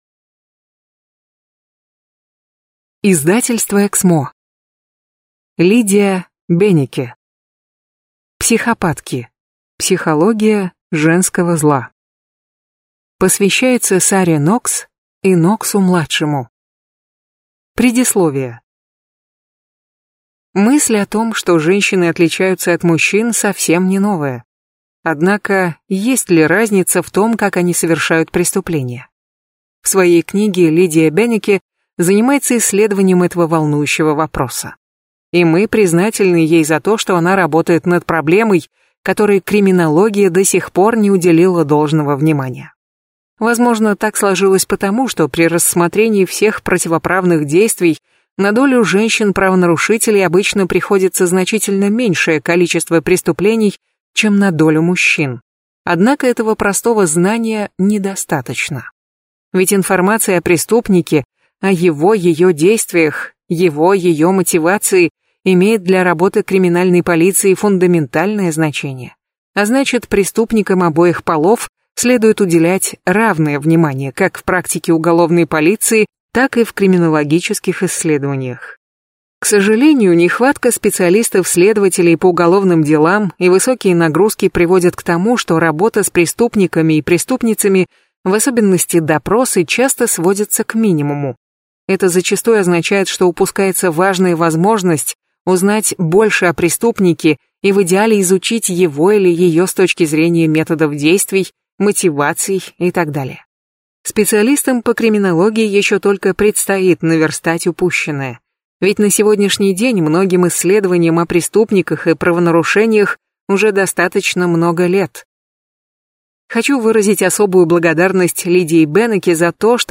Аудиокнига Психопатки. Психология женского зла | Библиотека аудиокниг